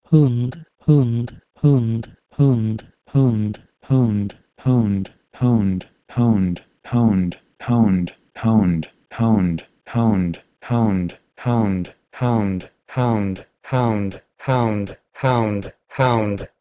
E.g. 7. Old English hund, "hound", had a close vowel, but now it's an open, back diphthong:
huund-to-hAUnd.wav